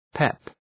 Προφορά
pep.mp3